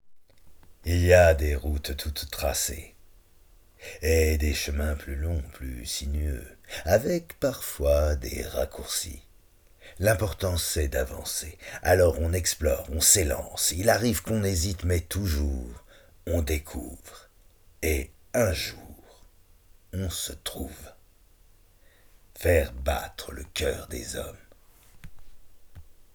Voix off
42 - 67 ans - Baryton